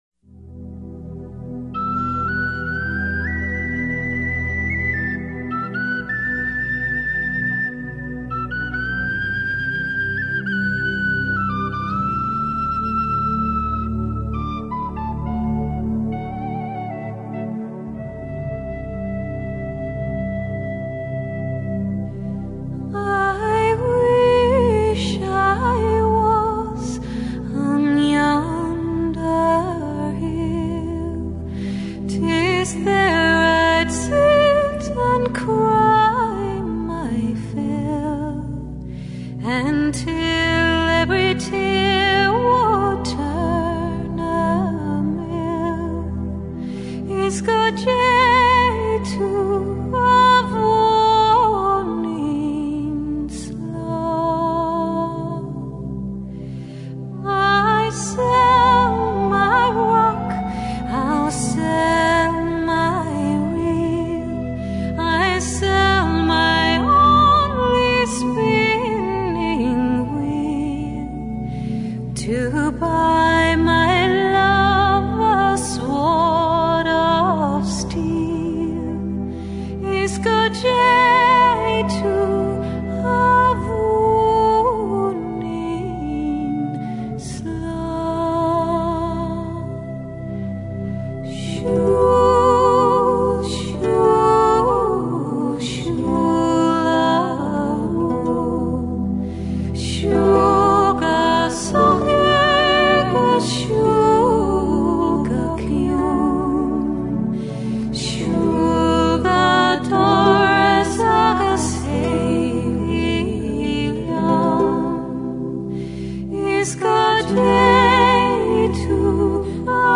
全碟的人声录得非常靓，精准的口型有强烈的实体感，有不食人间烟火的超脱感。